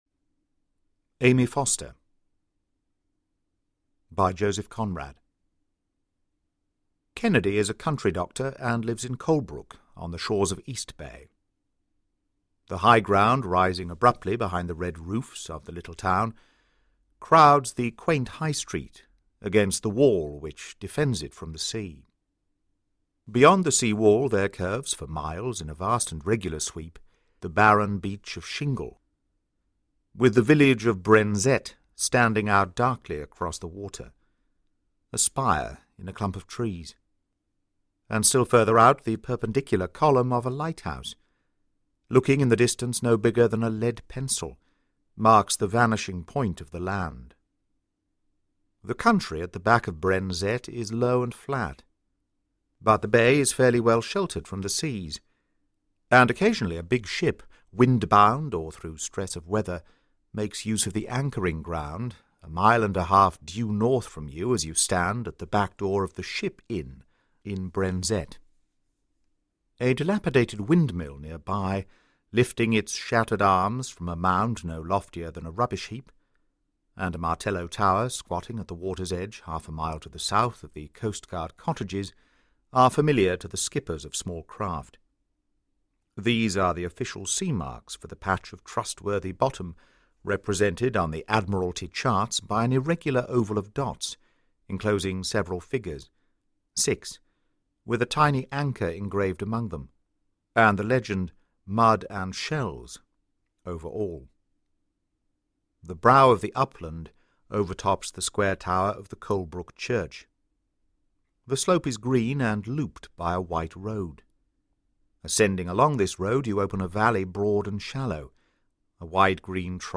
The Norwood Builder: Sherlock Holmes Solves a Brutal Case (Audiobook)